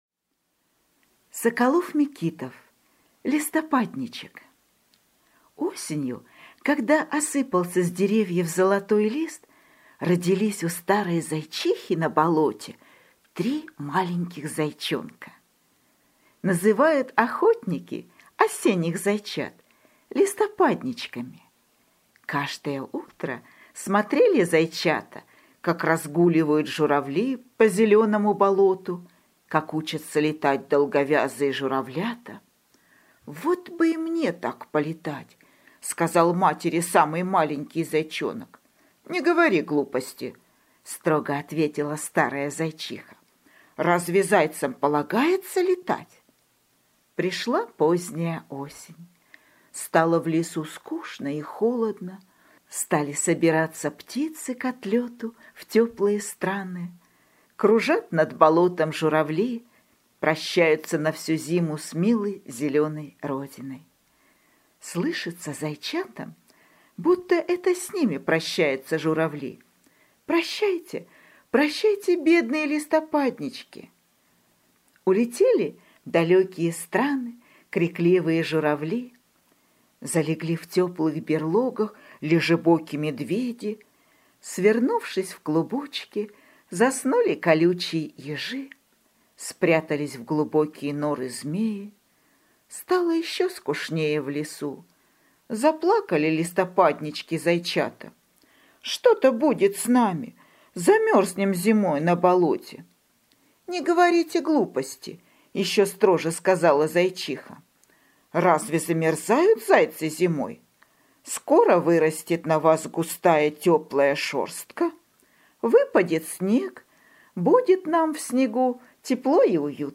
Листопадничек - аудиосказка Соколова-Микитова - слушать онлайн